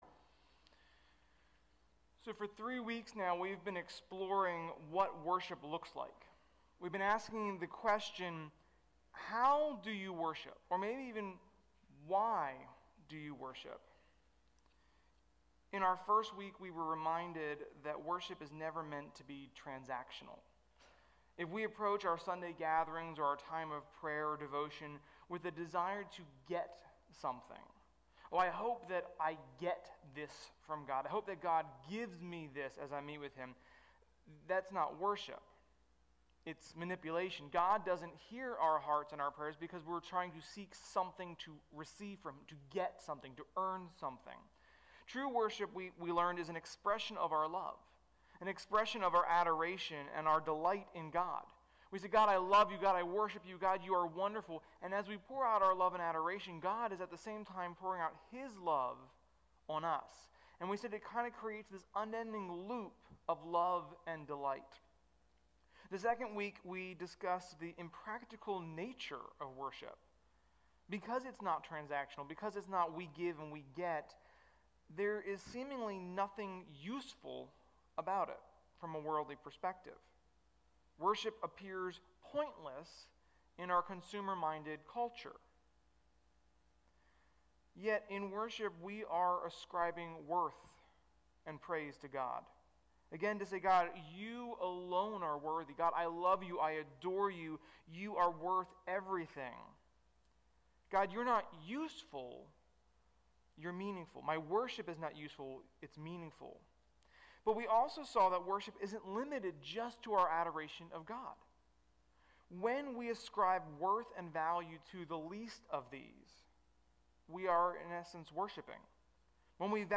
Sermon-1.31.21.mp3